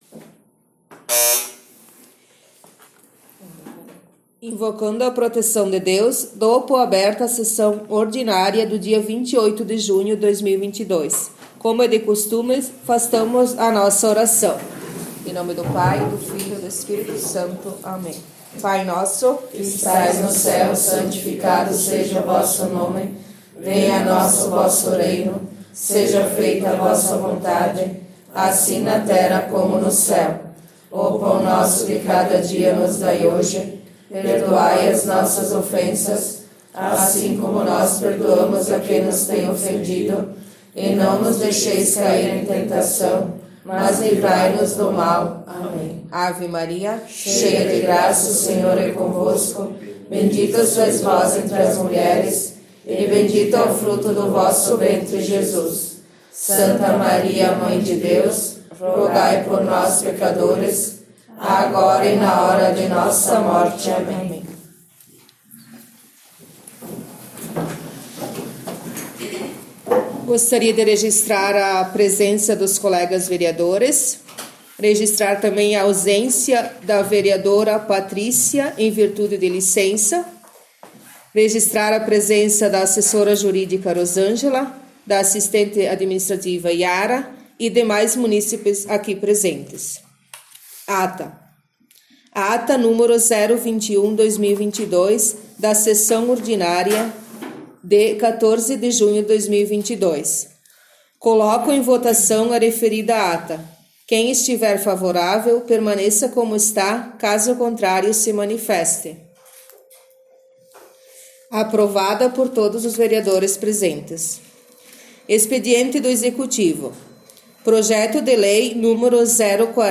17 - Sessão Ordinária 28 Junho